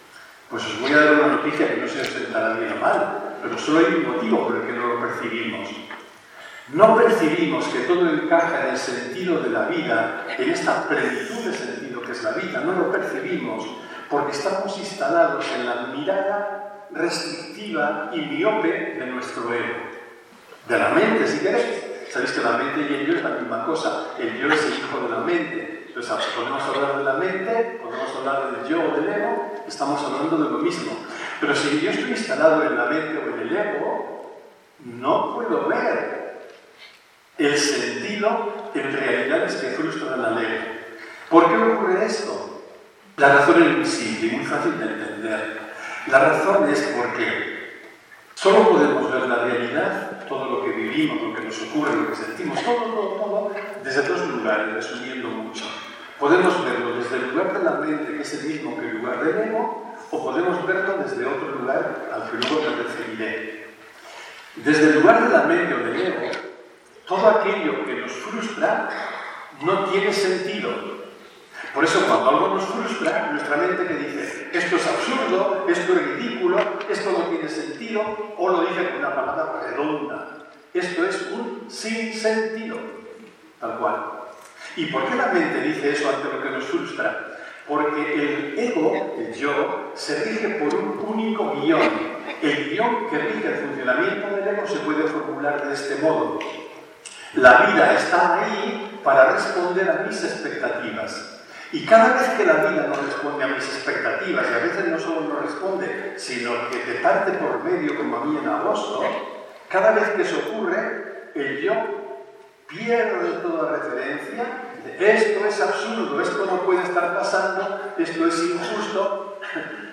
Logroño 27 y 28 de enero de 2024.